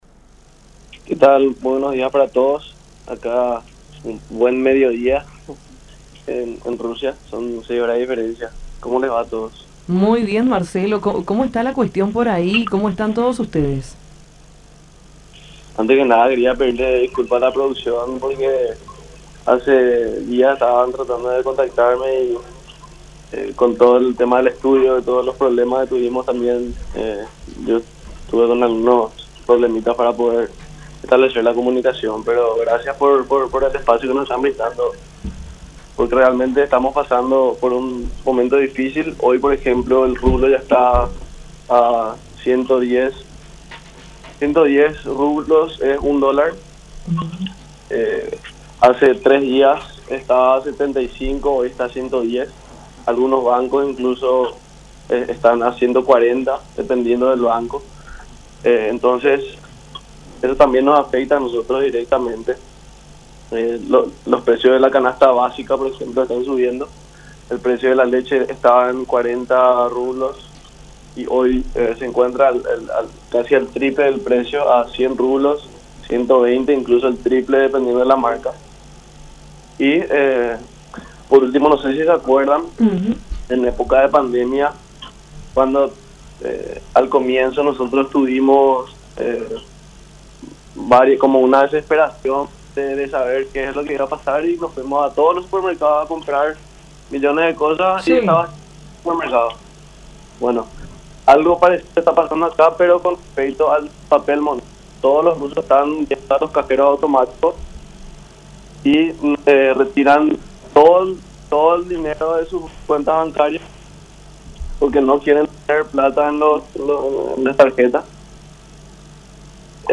en diálogo con Nuestra Mañana a través de La Unión